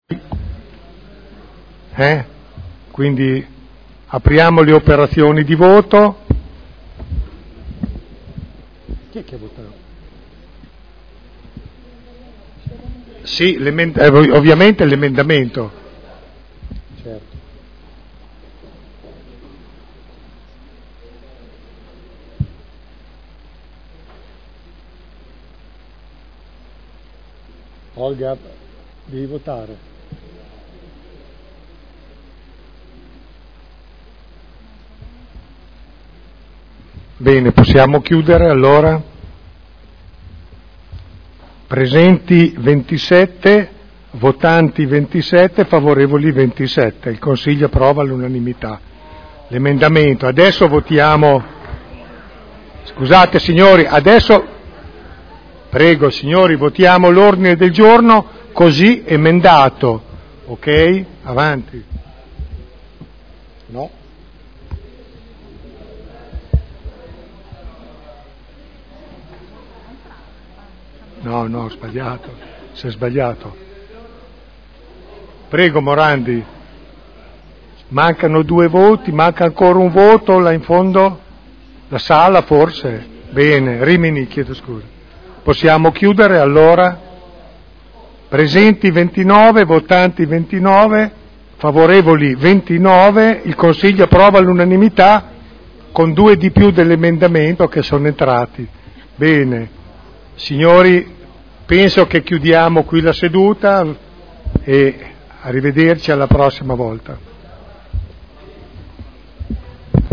Presidente — Sito Audio Consiglio Comunale
Seduta del 07/05/2012.